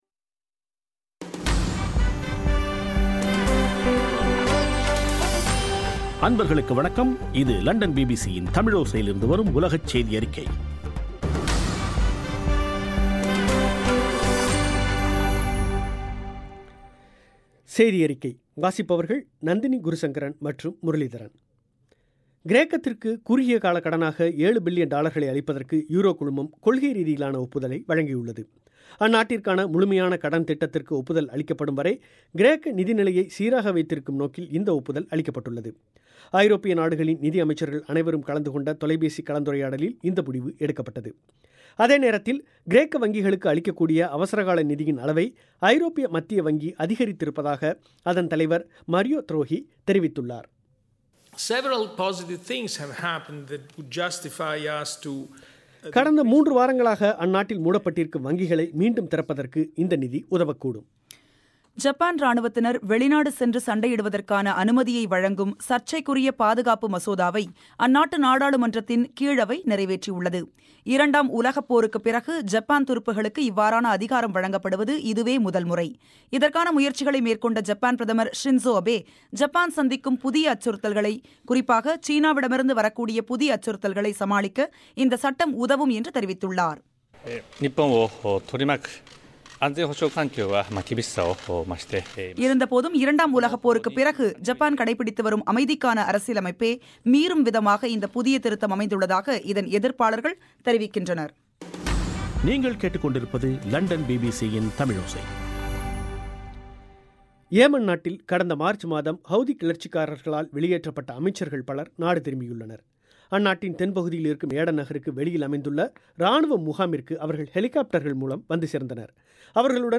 ஜூலை 16 பிபிசியின் உலகச் செய்திகள்